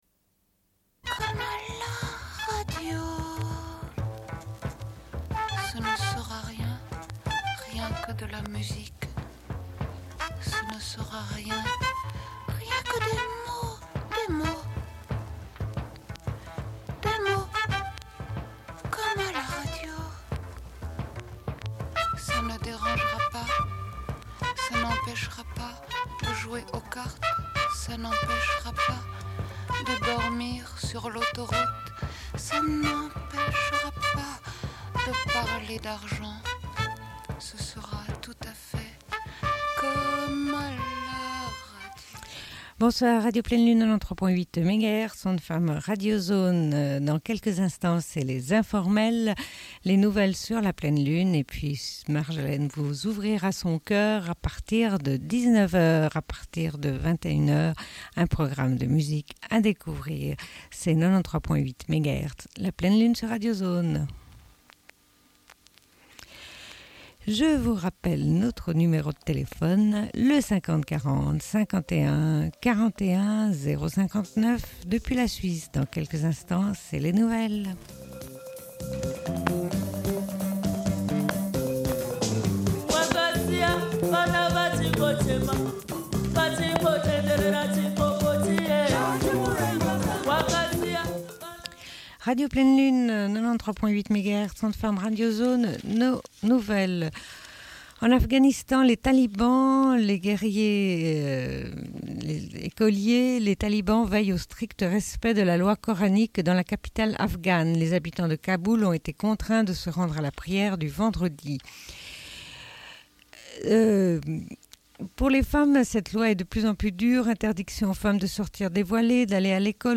Bulletin d'information de Radio Pleine Lune du 09.10.1996 - Archives contestataires
Une cassette audio, face B